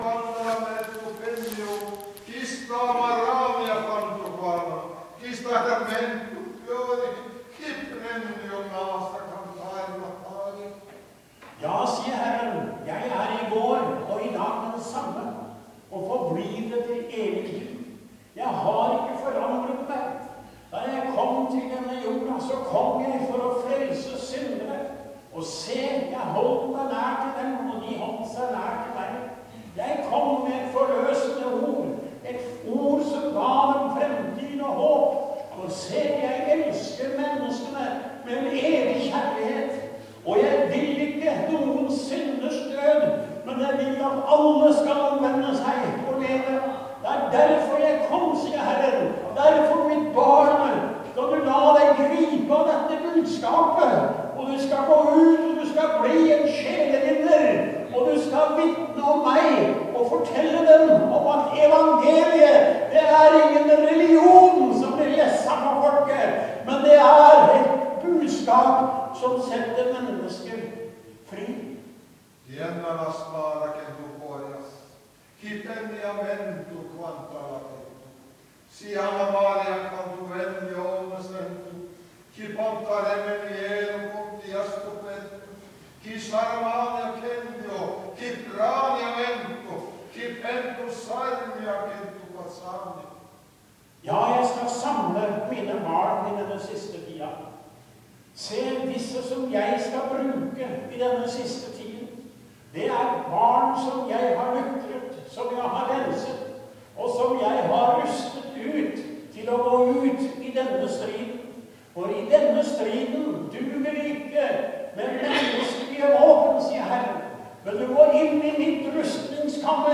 Tyding av tungetale: